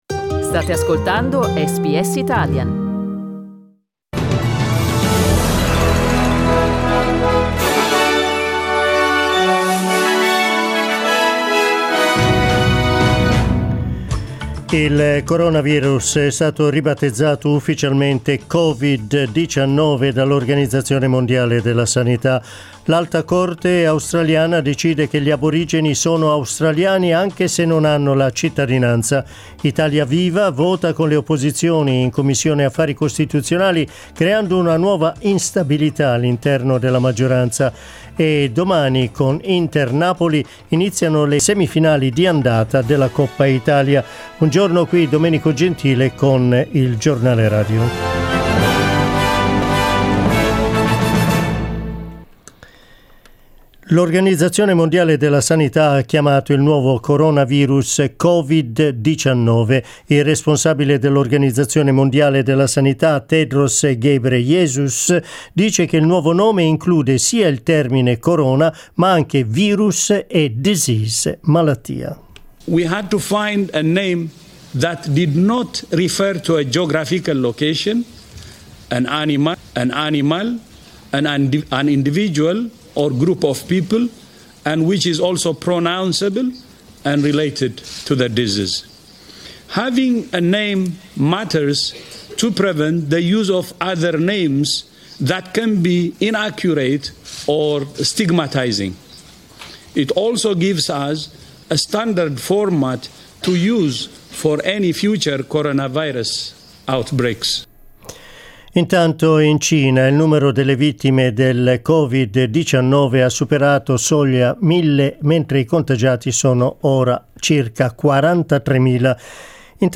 Giornale radio mercoledì 12 febbraio